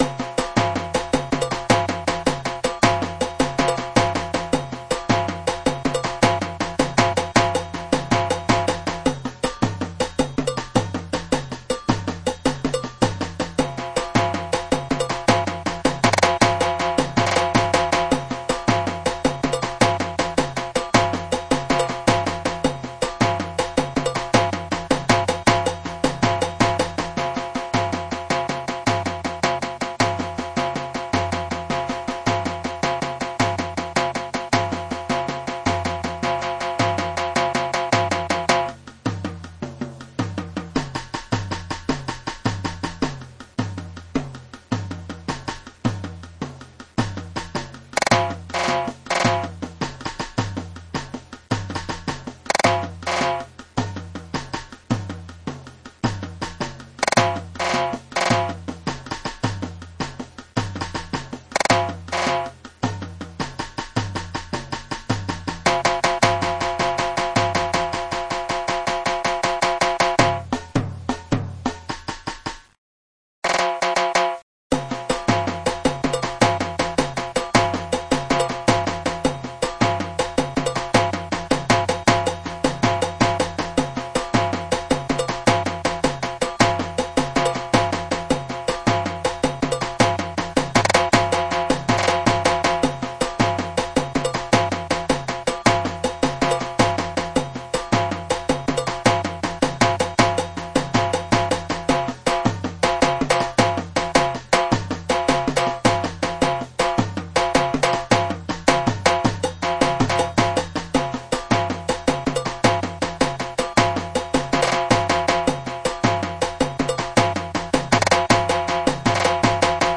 6/8 Groove with tam break and bell ( surdo ) break